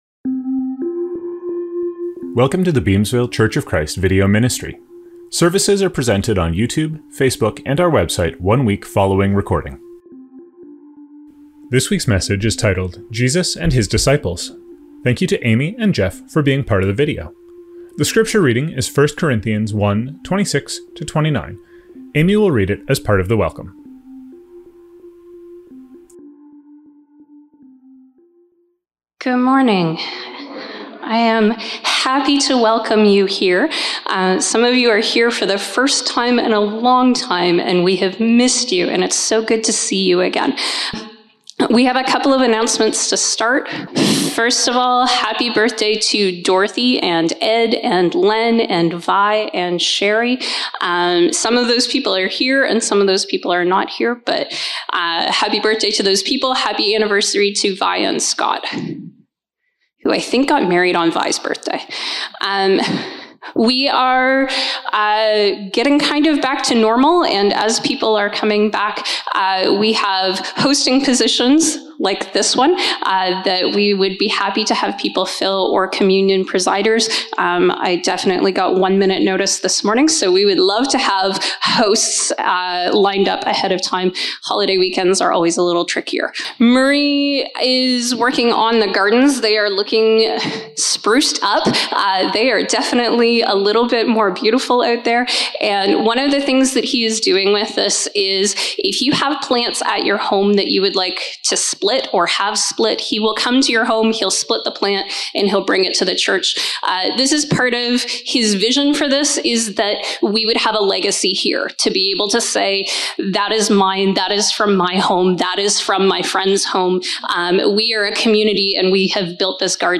Scriptures from this service include: Welcome: 1 Corinthians 1:26-29. Sermon: 1 Corinthians 1:26-29; Isaiah 43:7; Jeremiah 9:23-24; Luke 4:14-15; Mark 1:22; Matthew 8:18-22; Luke 9:61-62; Romans 12:2; 12:1; Acts 11:26.